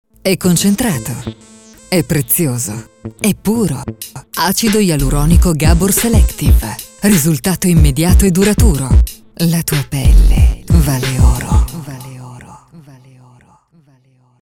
Spot audio per prodotti farmaceutici ed estetici